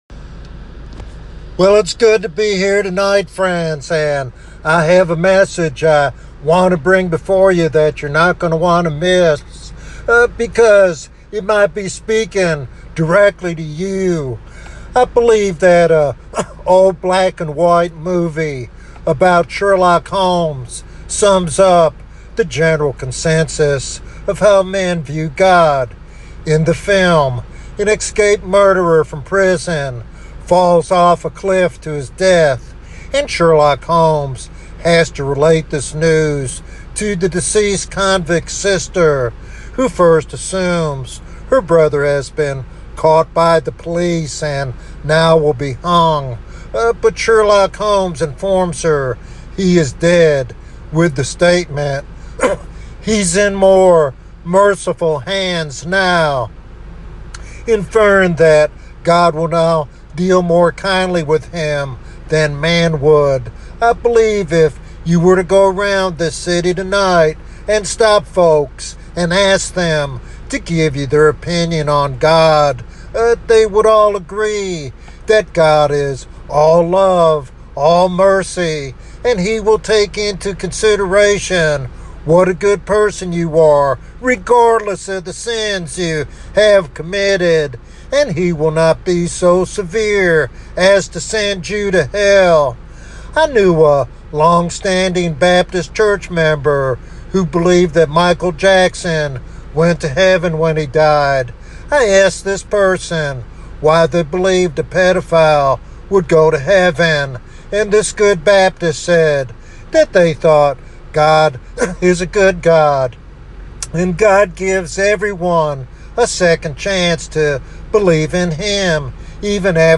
This sermon is a sobering reminder of the need to examine one's faith and embrace the gospel with urgency.